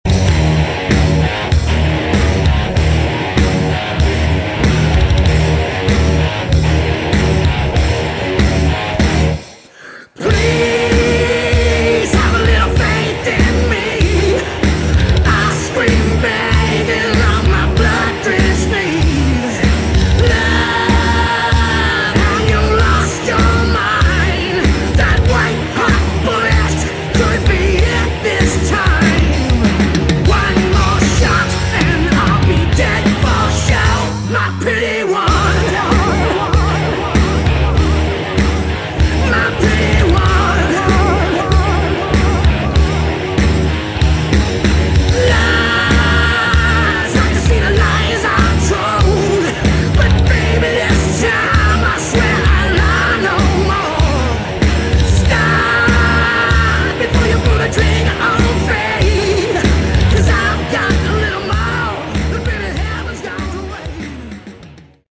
Category: Hard Rock
vocals, keyboard, guitar